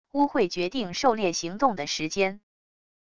巫会决定狩猎行动的时间wav音频生成系统WAV Audio Player